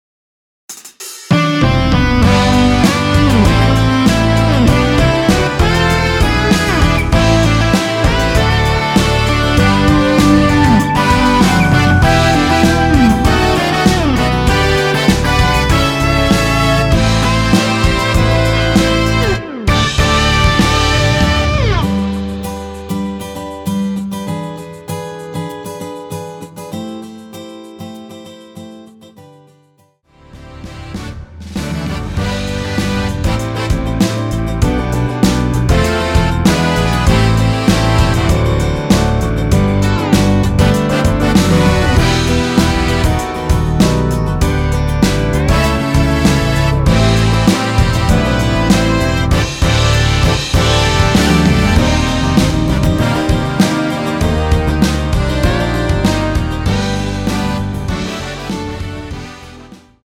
원키에서(-5)내린 MR입니다.
◈ 곡명 옆 (-1)은 반음 내림, (+1)은 반음 올림 입니다.
앞부분30초, 뒷부분30초씩 편집해서 올려 드리고 있습니다.
중간에 음이 끈어지고 다시 나오는 이유는